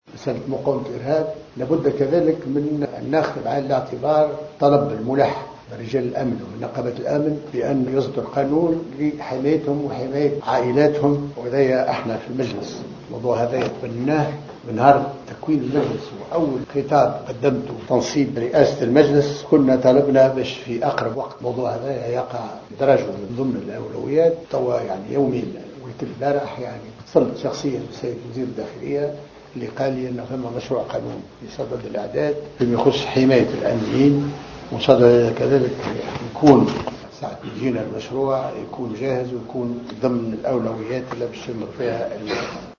قال رئيس مجلس نواب الشعب محمد الناصر خلال جلسة عقدت صباح اليوم بالمجلس إن قانون مقاومة الارهاب واصدار قانون لحماية الامنيين وعائلاتهم سيكون في مقدمة القوانين التي سيتم سنها في أقرب وقت.